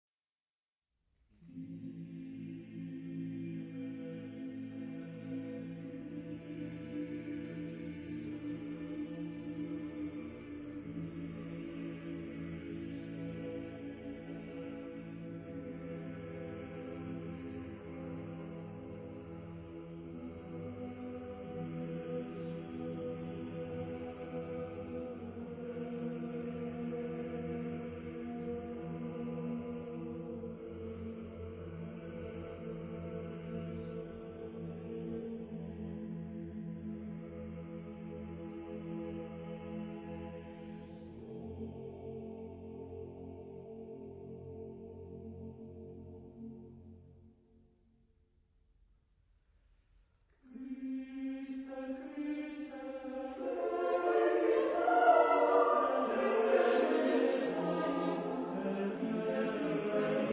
Genre-Style-Form: Sacred ; Mass ; Choir
Type of Choir: SATB  (4 mixed voices )
Tonality: tonal